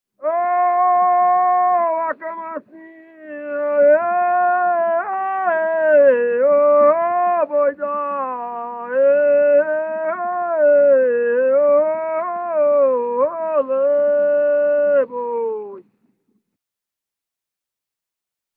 Aboio